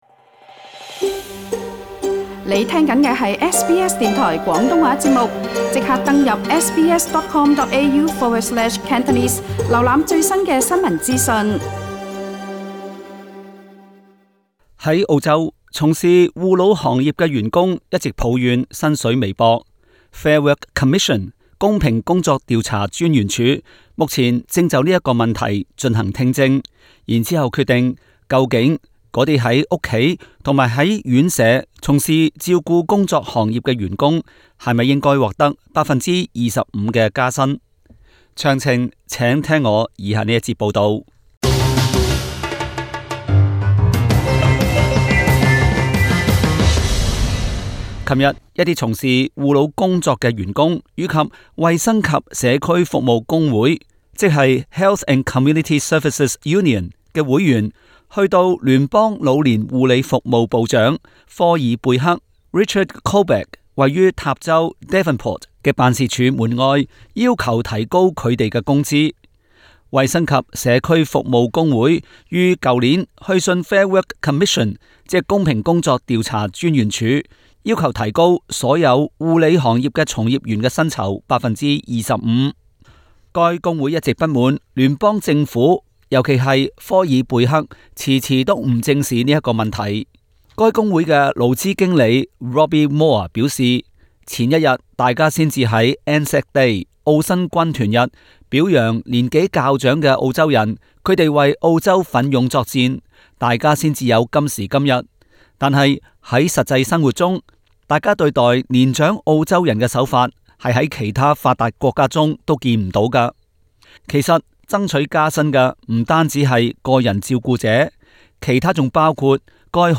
Source: AAP SBS廣東話節目 View Podcast Series Follow and Subscribe Apple Podcasts YouTube Spotify Download (6.06MB) Download the SBS Audio app Available on iOS and Android 在澳洲，從事照顧老人行業的員工，一直抱怨薪水微薄。